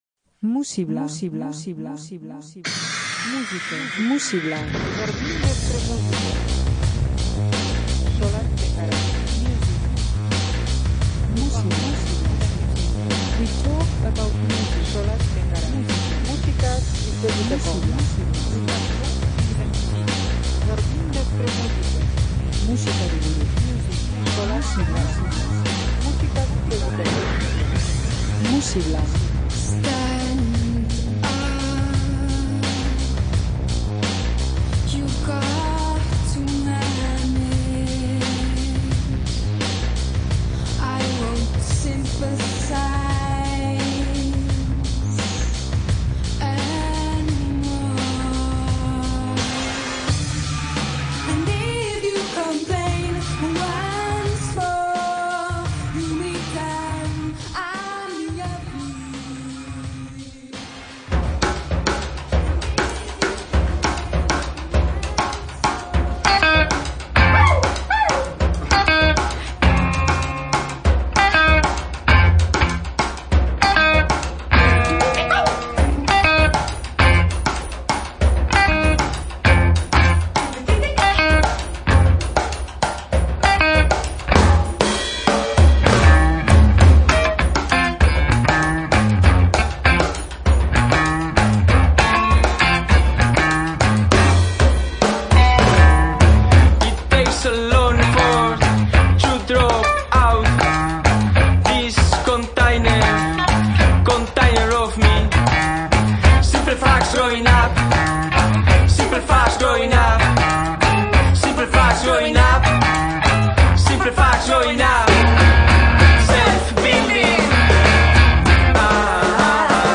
Saio honekin hasiera emango diogu elkarrizketa-saio sorta bati. Hemendik pasatuko dira Euskal talderik esanguratsuenak euren lan, ibilbide eta abarrei buruz hitz egiten.